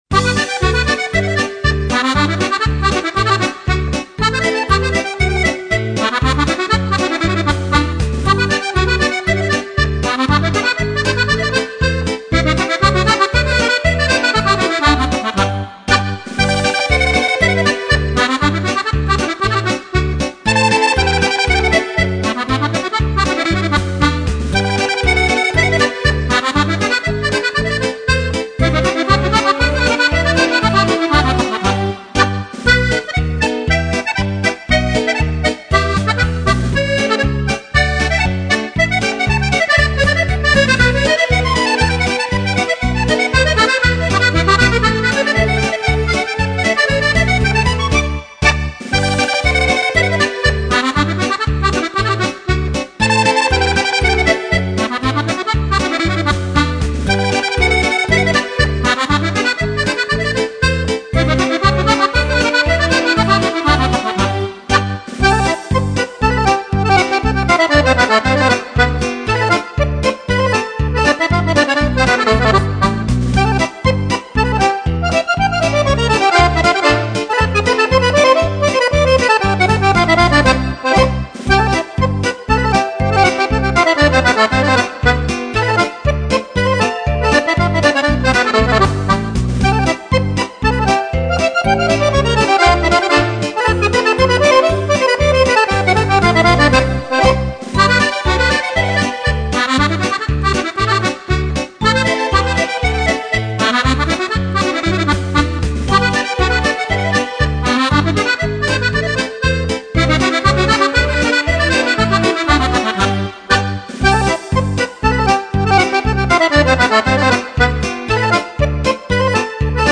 Polka
Compilation di brani per fisarmonica